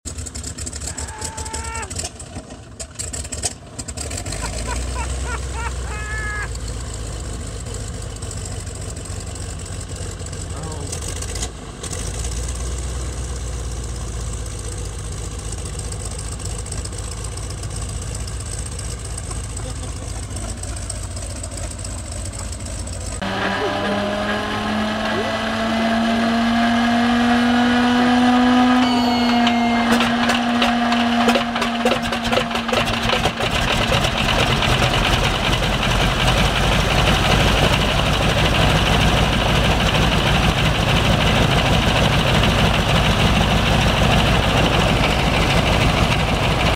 Big Old RADIAL AIRPLANE ENGINES sound effects free download
Big Old RADIAL AIRPLANE ENGINES Cold Start and Sound